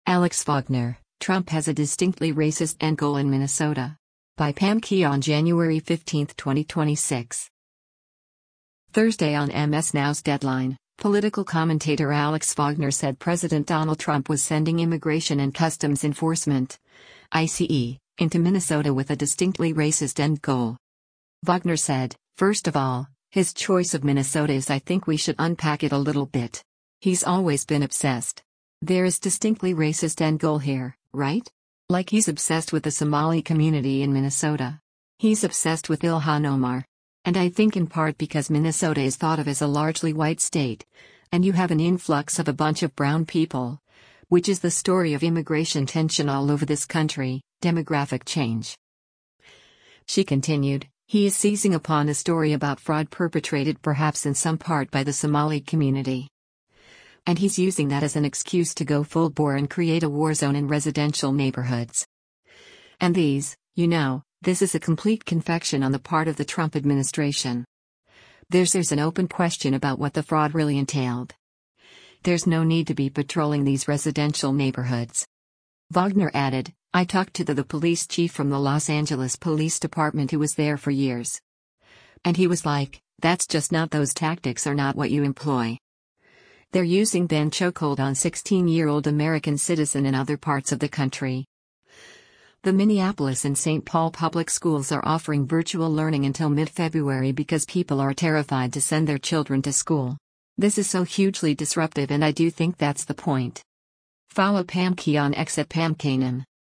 Thursday on MS NOW’s “Deadline,” political commentator Alex Wagner said President Donald Trump was sending Immigration and Customs Enforcement (ICE) into Minnesota with a “distinctly racist end goal.”